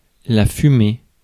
Ääntäminen
Synonyymit (Québecin ranska) boucane Ääntäminen France: IPA: [fyme] Haettu sana löytyi näillä lähdekielillä: ranska Käännös Konteksti Ääninäyte Substantiivit 1. smoke Kanada US 2. steam US 3. spoor 4. smoking US Suku: f .